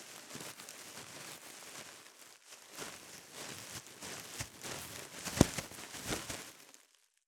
654ゴミ袋,スーパーの袋,袋,買い出しの音,ゴミ出しの音,袋を運ぶ音,
効果音